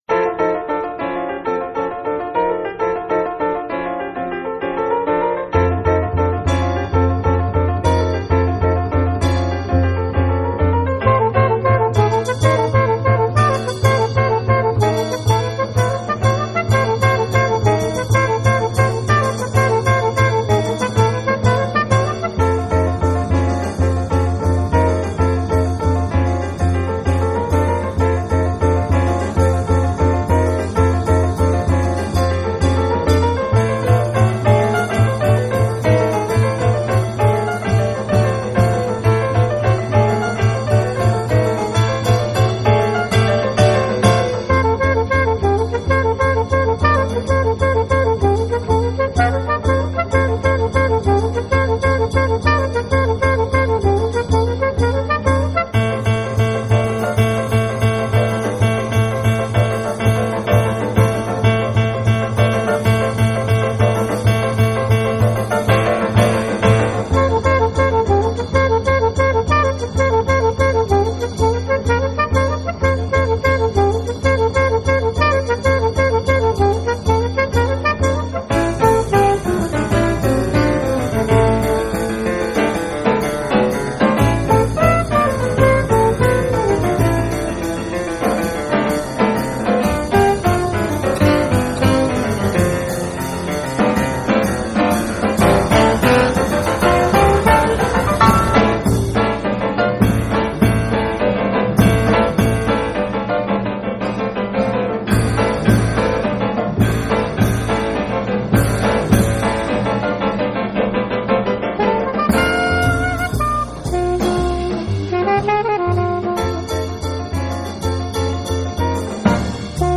Jazz, Cool Jazz